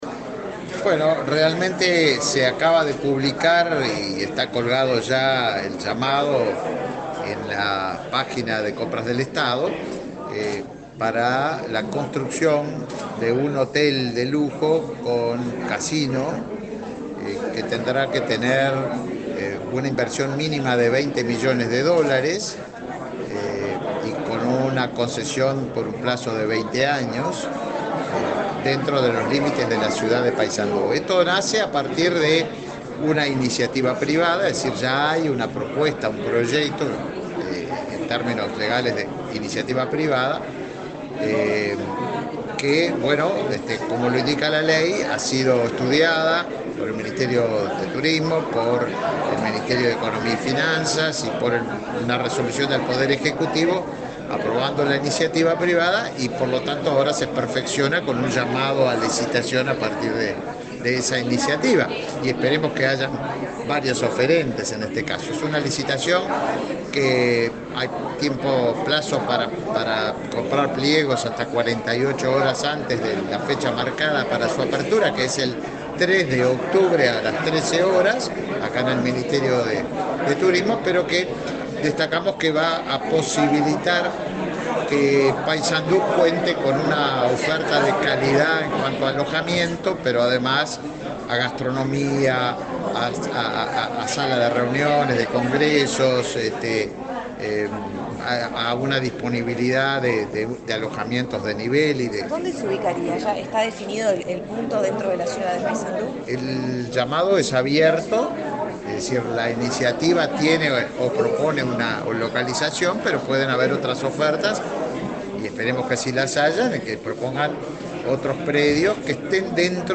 Declaraciones del ministro de Turismo, Tabaré Viera
El ministro de Turismo, Tabaré Viera, dialogó con la prensa luego de participar del lanzamiento de la 19ª edición de Cocinarte Uruguay, que se